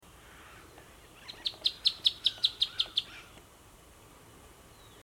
Chaco Earthcreeper (Tarphonomus certhioides)
Life Stage: Adult
Location or protected area: Reserva Provincial Parque Luro
Condition: Wild
Certainty: Photographed, Recorded vocal
Bandurrita-chaquena.mp3